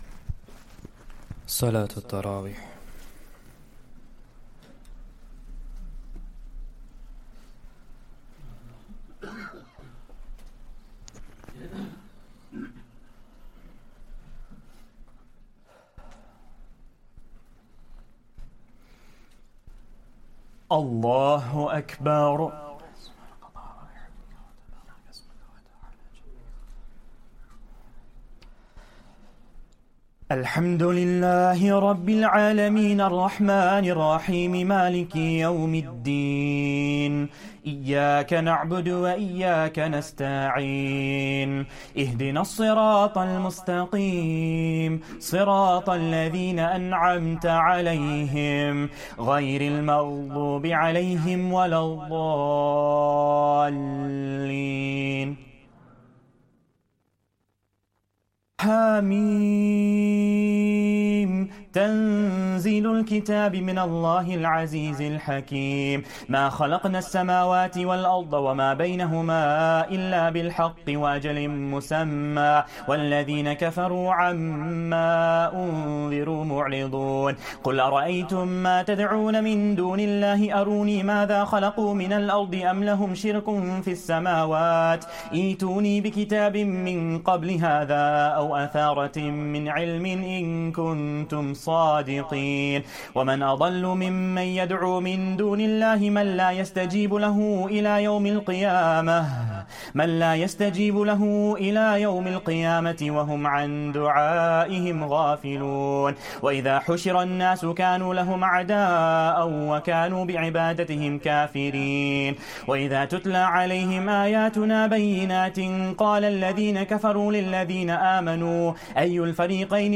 Taraweeh Prayer 22nd Ramadan